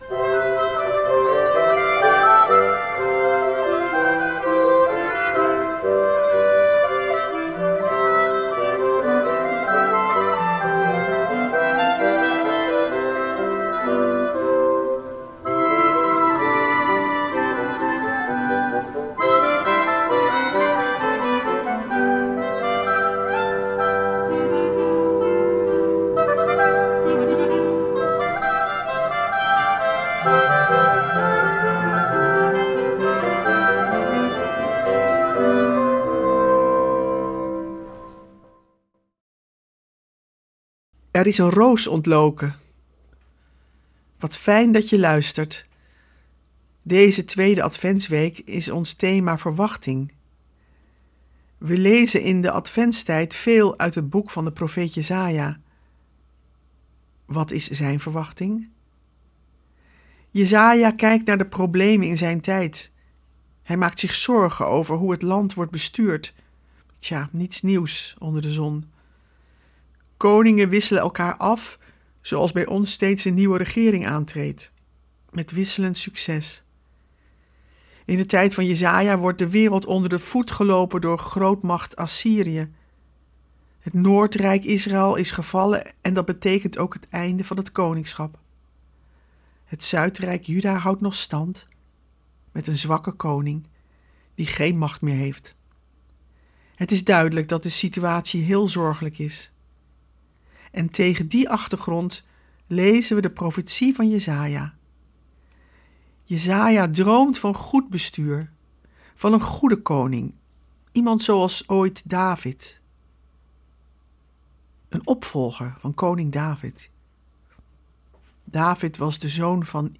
Met muziek en een actuele reflectie.